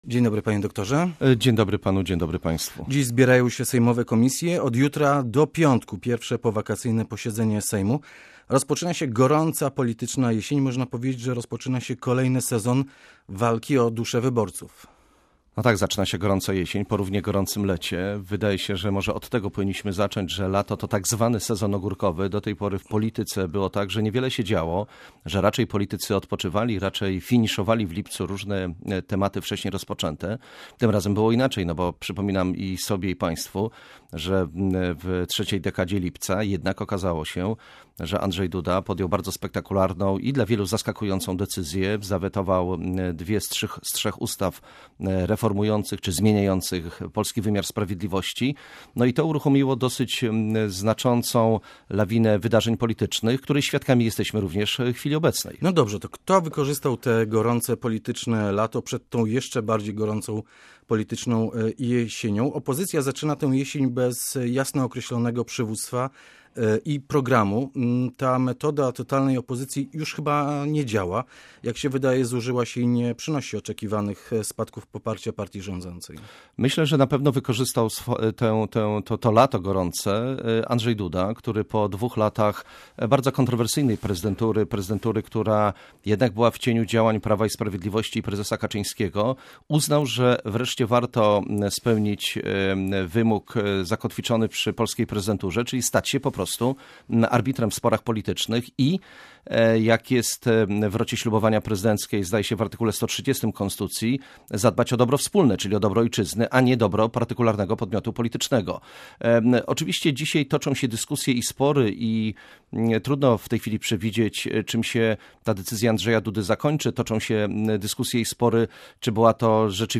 Zdaniem gościa Radia Gdańsk istnieje jednak możliwość, że sama ekspertyza nie wniesie wiele i nie będzie pociągała za sobą żadnych konkretnych działań.